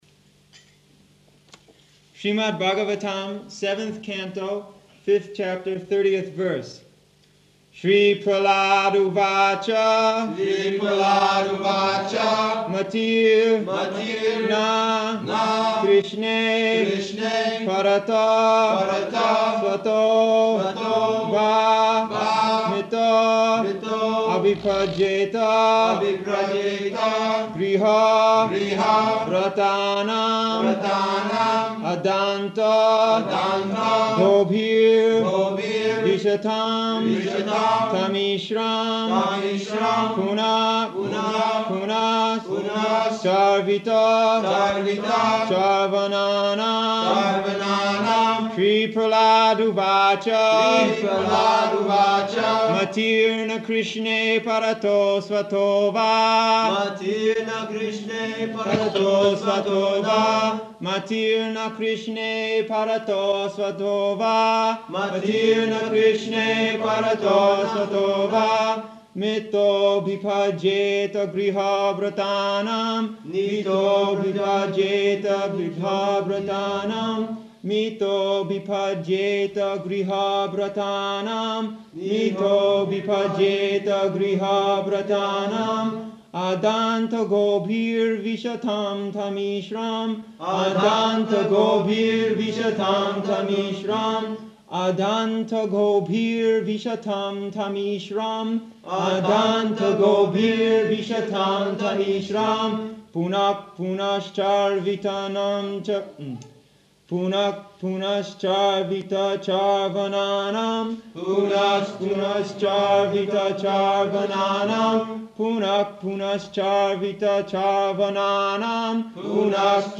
October 2nd 1975 Location: Mauritius Audio file
[leads chanting of verse, etc.]